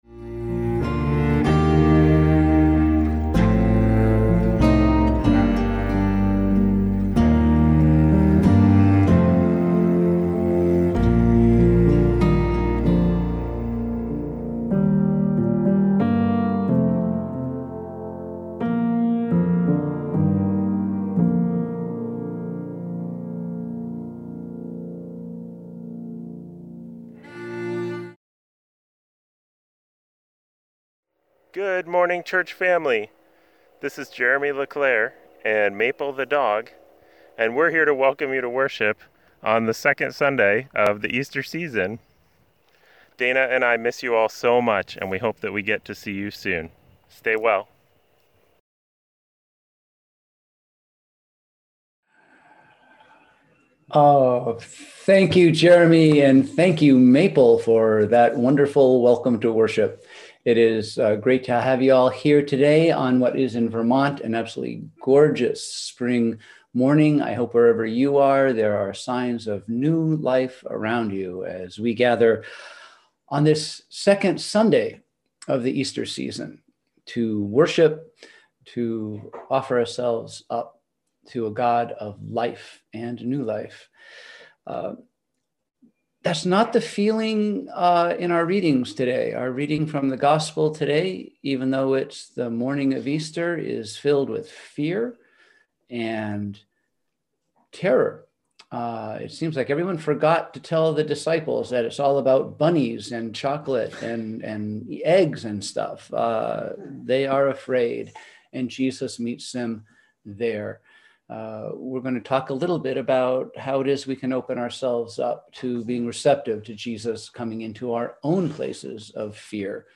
We held virtual worship on Sunday, April 11, 2021 at 10AM!